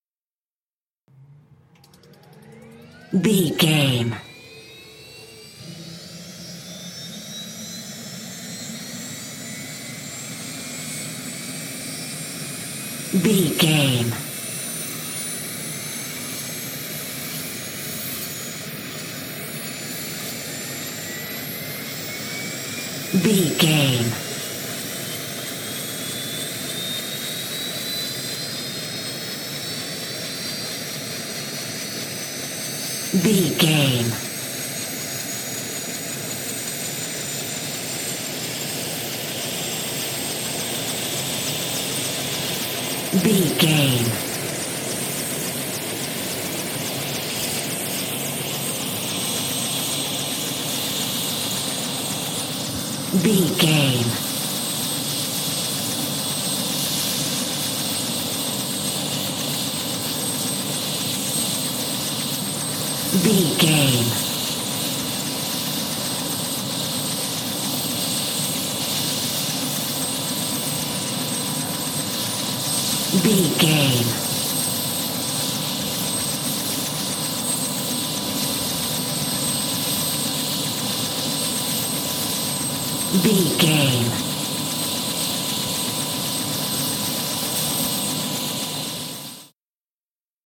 Helicopter takeoff ext 371
Sound Effects